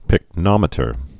(pĭk-nŏmĭ-tər)